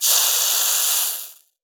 cooking_sizzle_burn_fry_11.wav